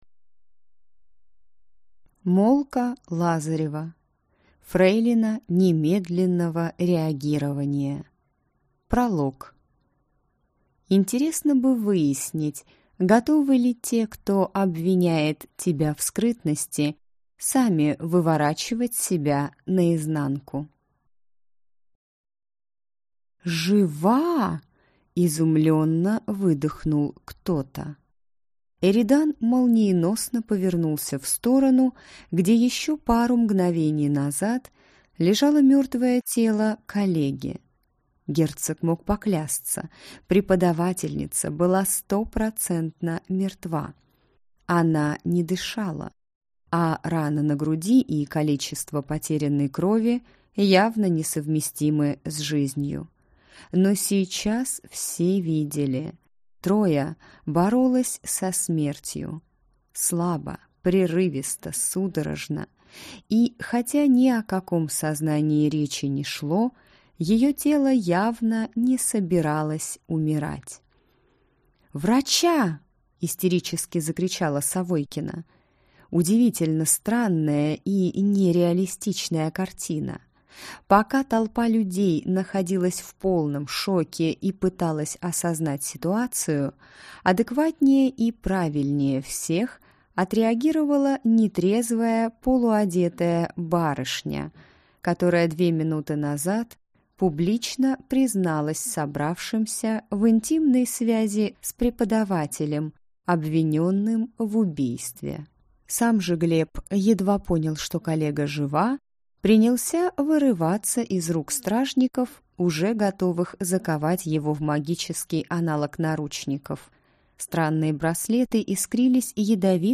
Аудиокнига Фрейлина немедленного реагирования - купить, скачать и слушать онлайн | КнигоПоиск